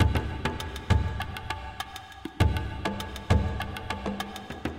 描述：这是在印度孟买北部一个名为Khar的社区制作的鼓录音。
标签： 贝司 打击乐 音乐 打击乐 印度 节拍 孟买 节奏
声道立体声